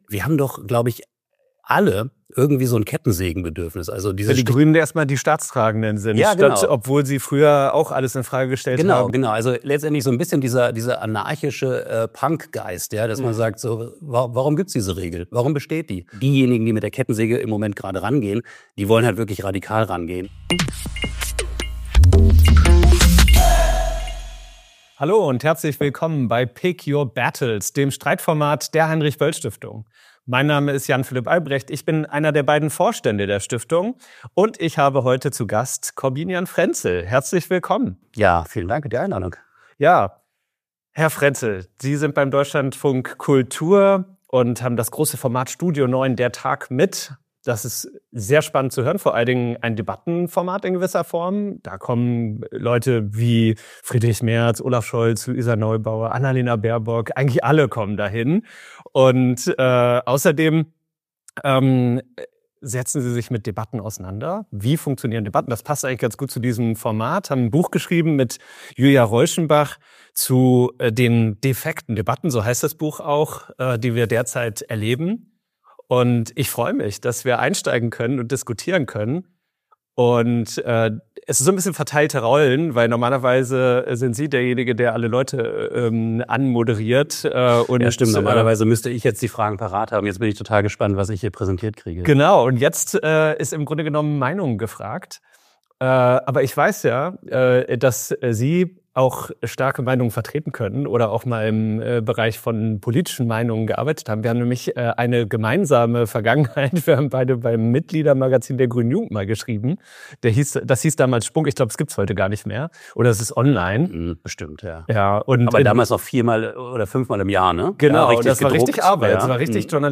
Eine konstruktive Debatte über grüne Politik, gesellschaftliche Spaltungen – und die Frage: Wo muss sich dringend etwas ändern?